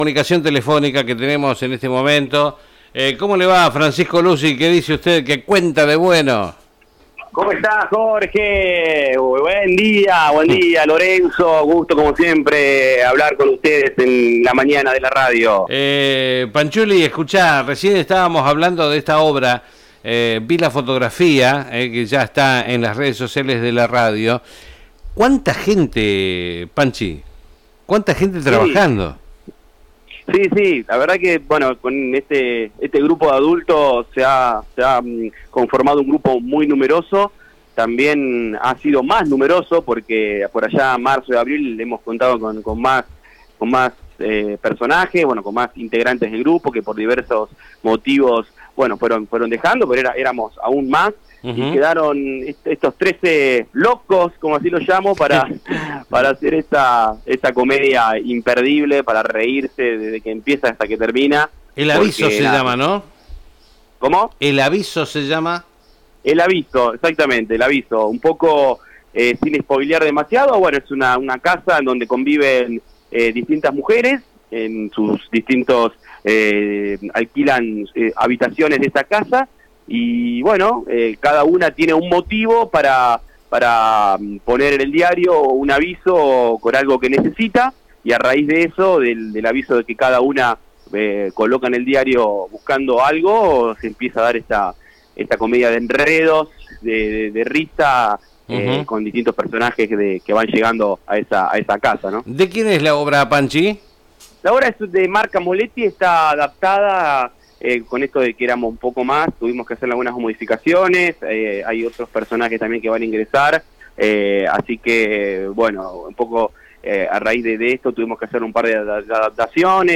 Comunicación telefónica